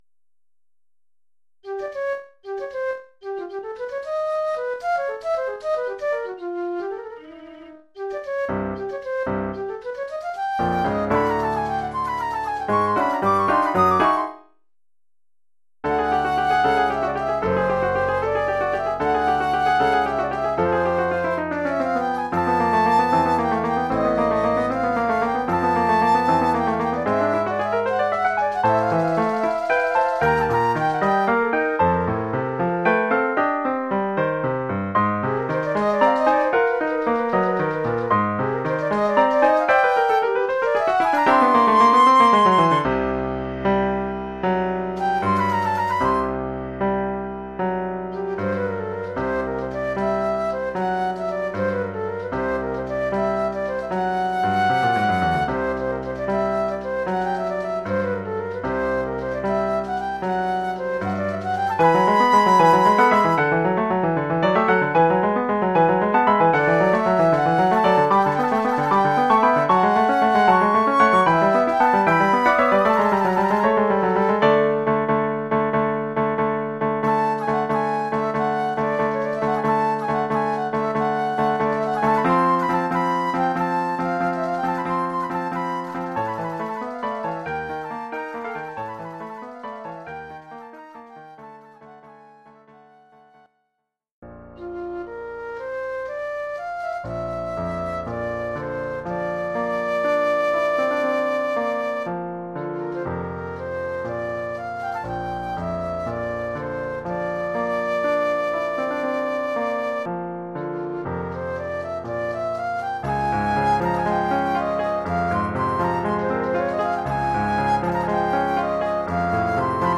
Oeuvre pour flûte et piano.